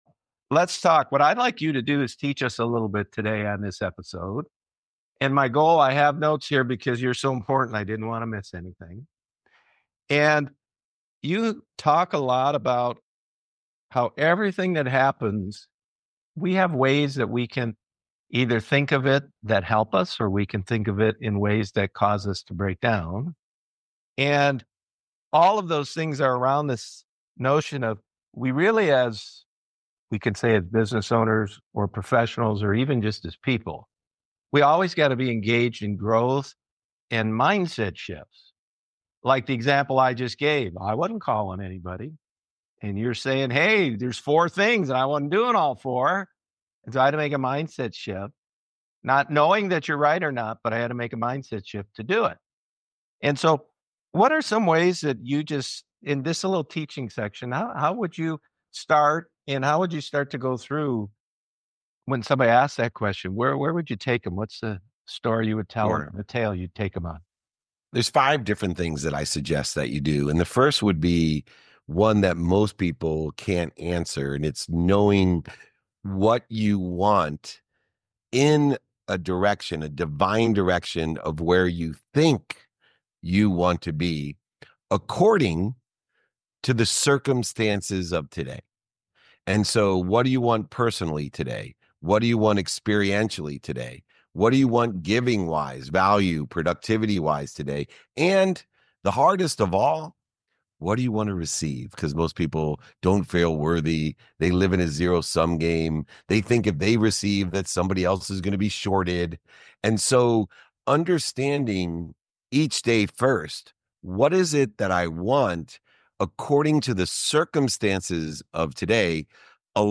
for a thought-provoking conversation about mindset